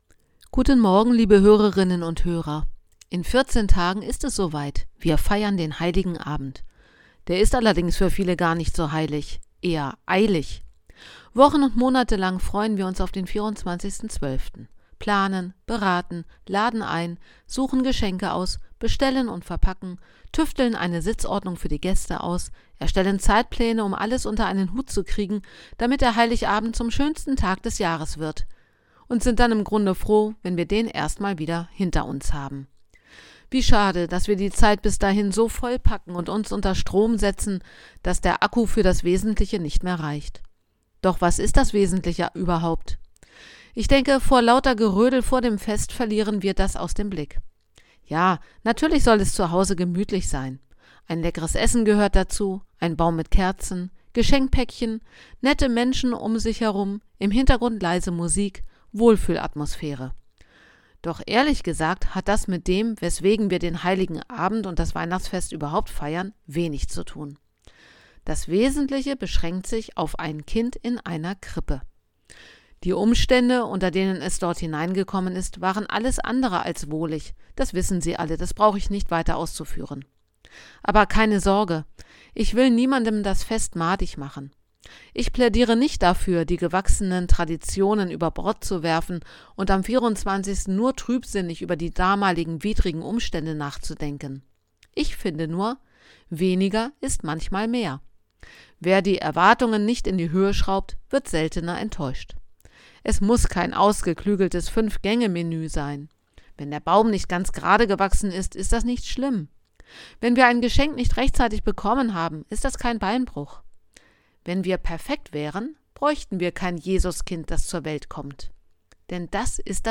Radioandacht vom 10. Dezember